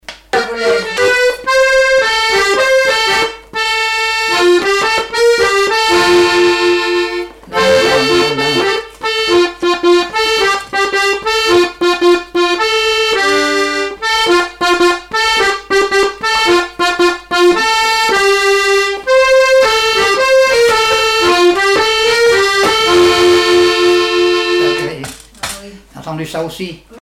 Répertoire du musicien sur accordéon chromatique
Pièce musicale inédite